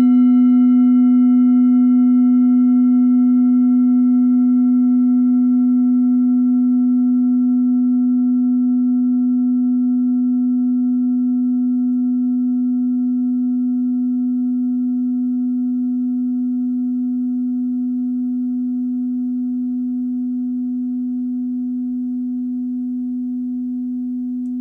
Klangschalen-Typ: Bengalen
Klangschale Nr.4
Gewicht = 810g
Durchmesser = 17,1cm
(Aufgenommen mit dem Filzklöppel/Gummischlegel)
klangschale-set-1-4.wav